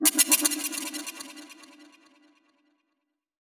/audio/sounds/Extra Packs/Dubstep Sample Pack/FX/